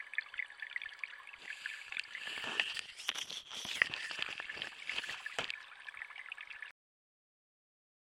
水听器 " 水听器池塘吸水
描述：从Harlaxton庄园的池塘录制的水听器。
Tag: 池塘 水听器